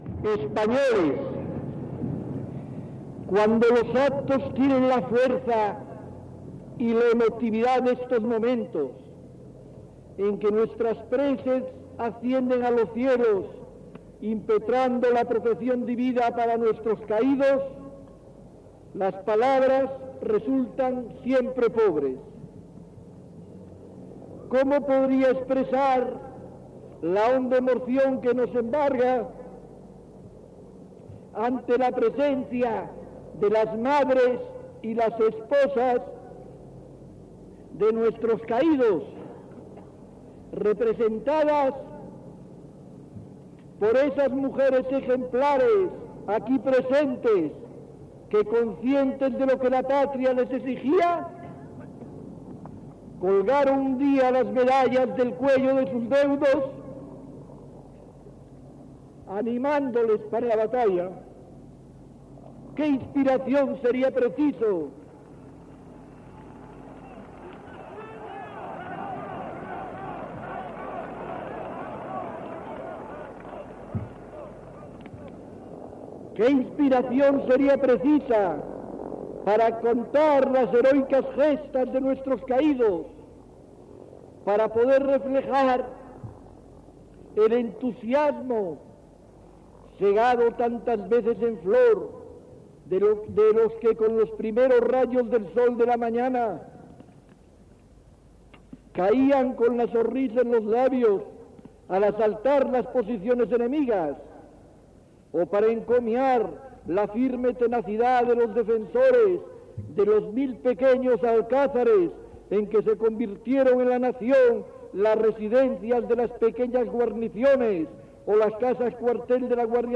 Discurs del "generalísimo" Franco en la inauguració del Valle de los Caídos al valle de Cuelgamuros de la Sierra de Guadarrama, en el municipi de San Lorenzo de El Escorial.
Informatiu